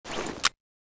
cz52_draw.ogg